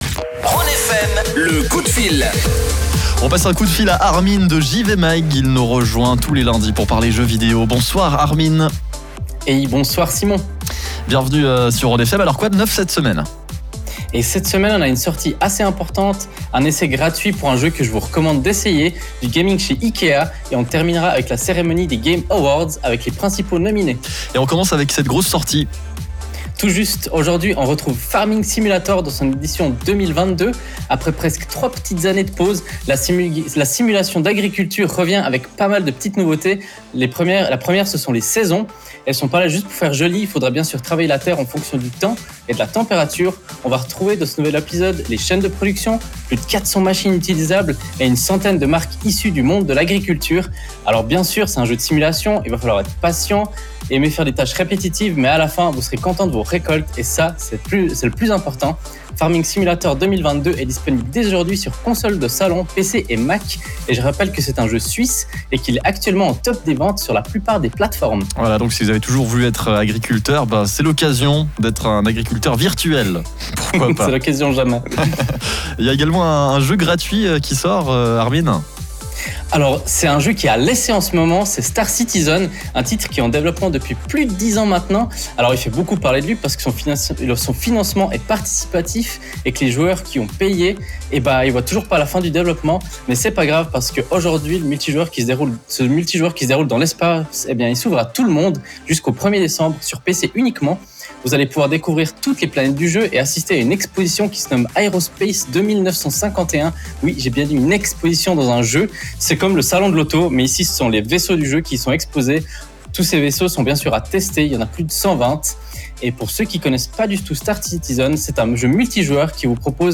C’est lundi, et pour nous le lundi, c’est synonyme de chronique radio !
Aujourd’hui c’est l’occasion de parler de Farming Simulator 22, Star Citzen, ou encore de IKEA Suisse et de son long stream. Vous pouvez réécouter juste en dessous le direct, ou retrouver les résumés plus bas.